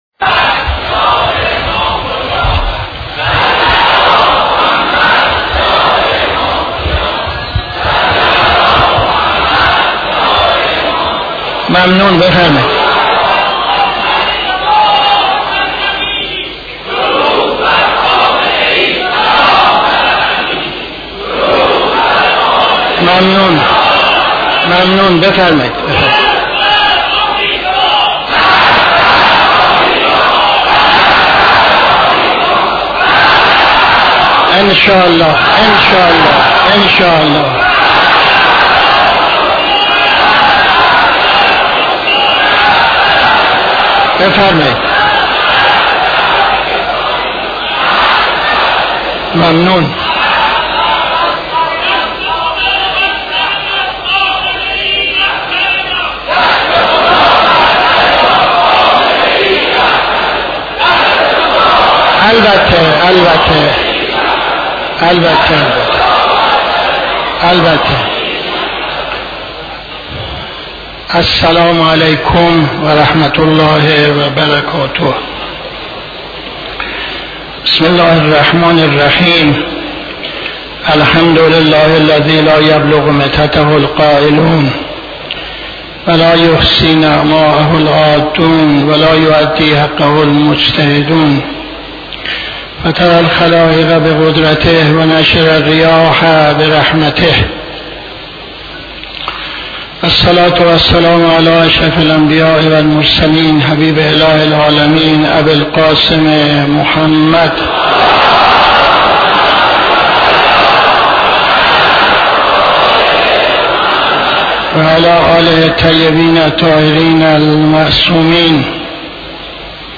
خطبه اول نماز جمعه 20-09-77